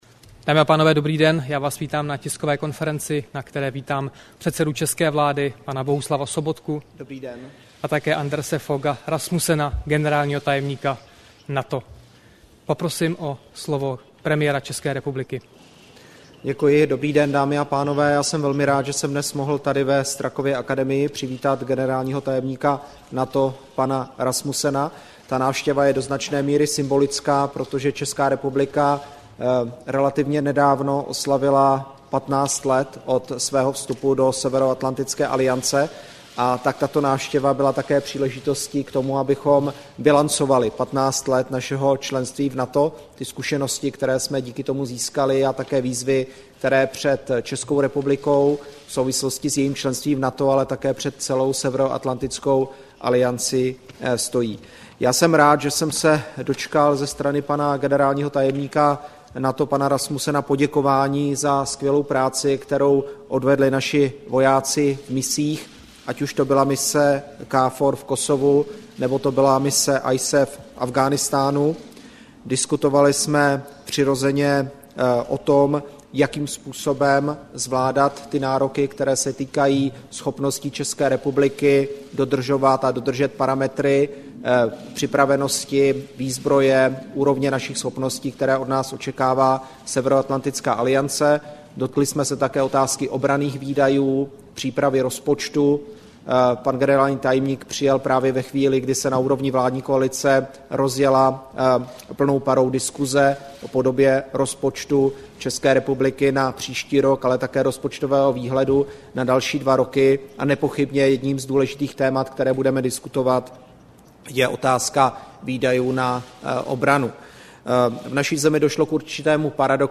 Tisková konference po jednání předsedy vlády ČR Bohuslava Sobotky s generálním tajemníkem NATO Andersem F. Rasmussenem, 10. dubna 2014